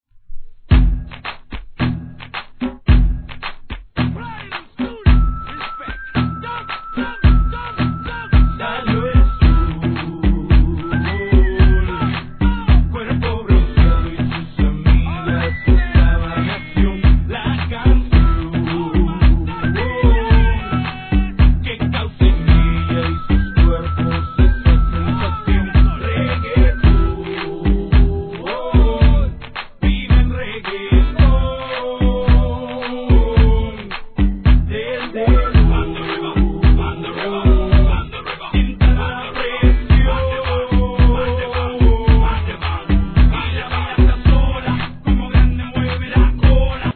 1. HIP HOP/R&B
■REGGAETON